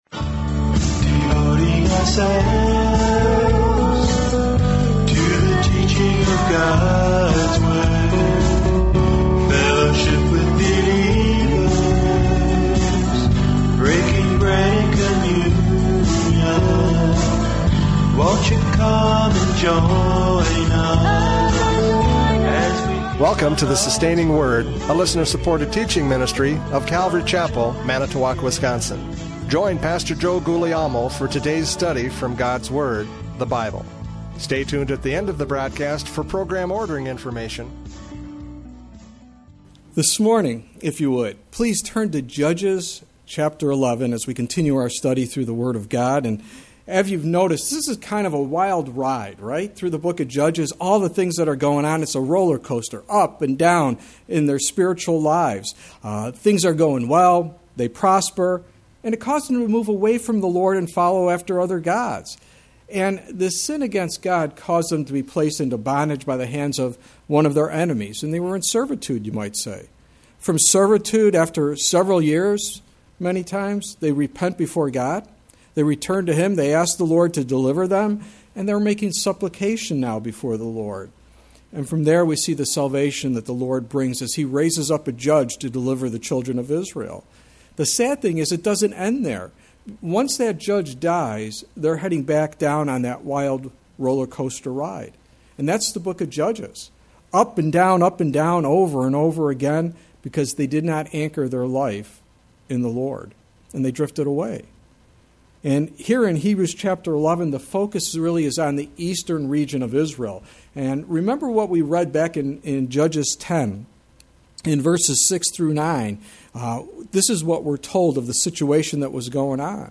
Judges 11:12-28 Service Type: Radio Programs « Judges 11:1-11 Love Shines Through!